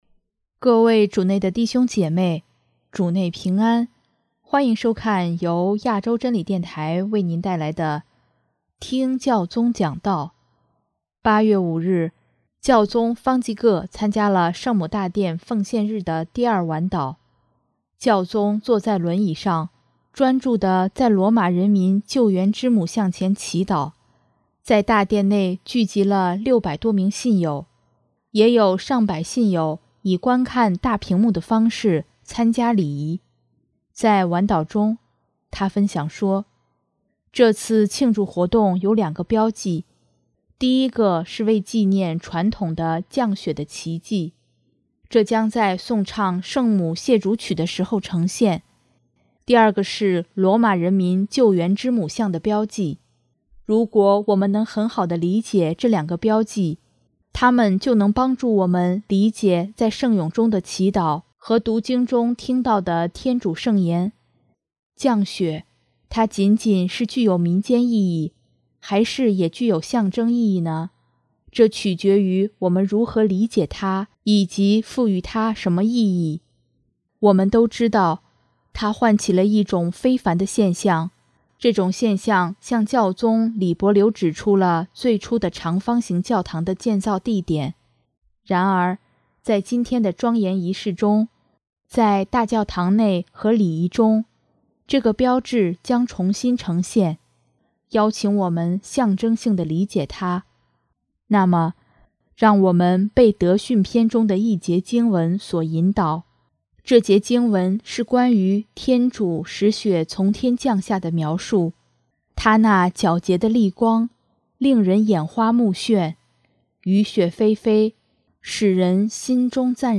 8月5日，教宗方济各参加了圣母大殿奉献日的第二晚祷，教宗坐在轮椅上，专注地在罗马人民救援之母像前祈祷，在大殿内聚集了600多名信友，也有上百信友以观看大屏幕的方式参加礼仪。